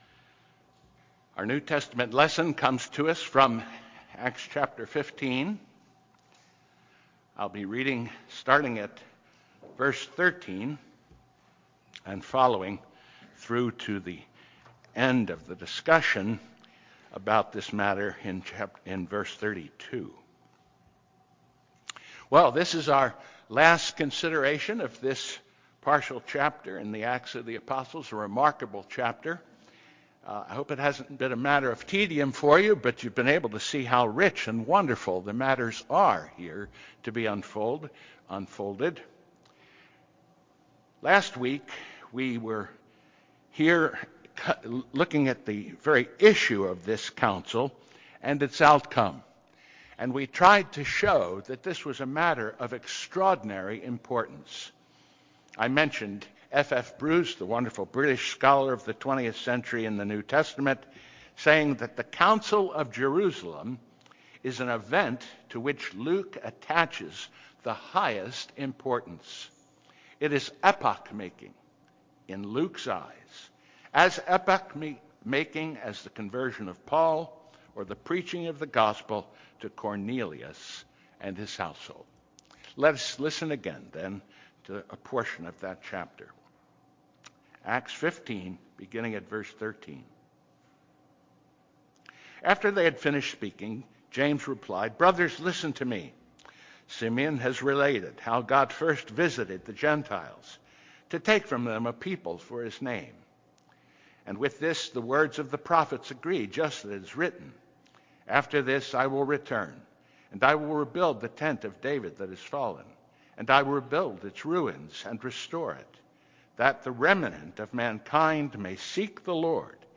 The Jerusalem Council Decision and Reception: Sermon on Acts 15:19-35 - New Hope Presbyterian Church